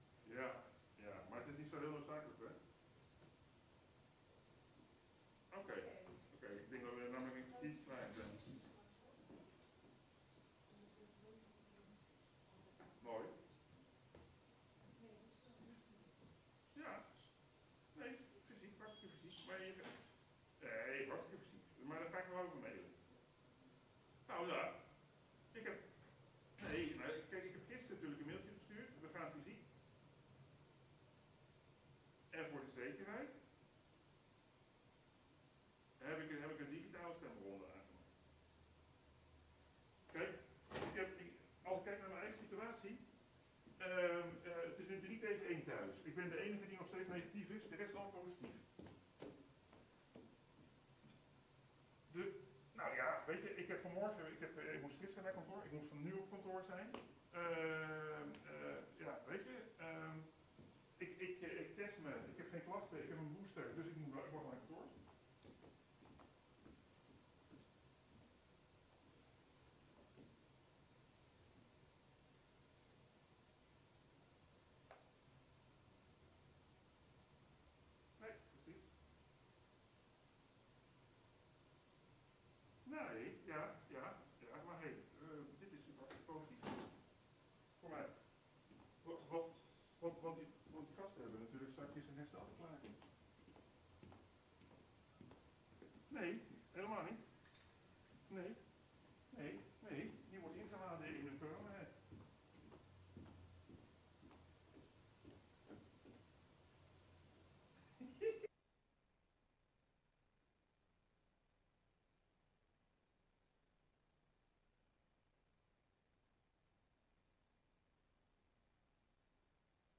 Openbare zitting van het centraal stembureau
Locatie: Raadzaal